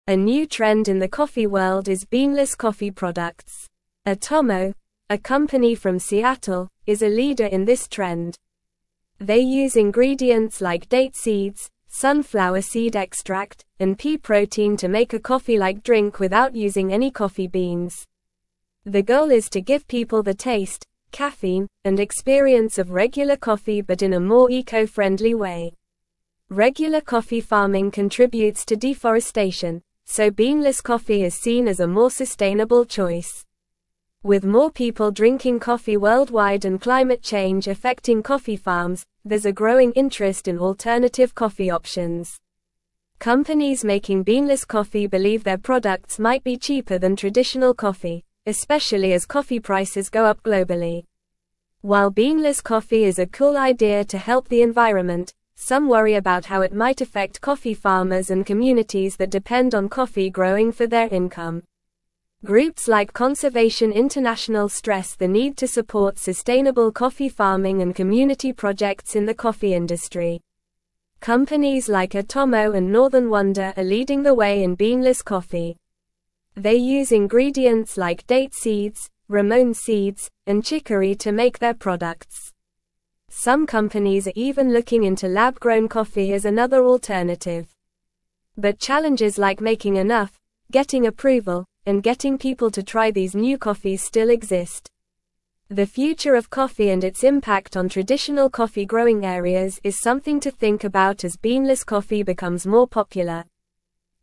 Normal
English-Newsroom-Upper-Intermediate-NORMAL-Reading-Emerging-Trend-Beanless-Coffee-Revolutionizing-the-Industry.mp3